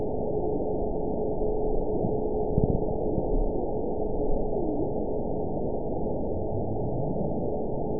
event 922169 date 12/27/24 time 22:32:59 GMT (11 months, 1 week ago) score 9.42 location TSS-AB04 detected by nrw target species NRW annotations +NRW Spectrogram: Frequency (kHz) vs. Time (s) audio not available .wav